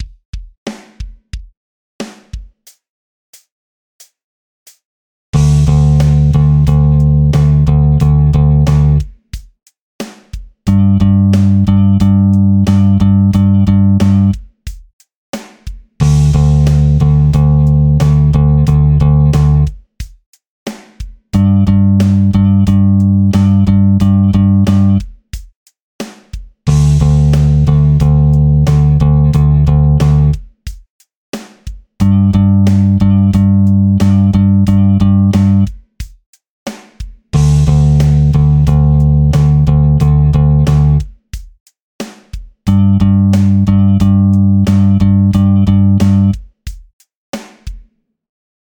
6．開放弦と長い音符／休符を使用したベース練習フレーズ７選！
6．裏拍から長休符が始まる練習フレーズ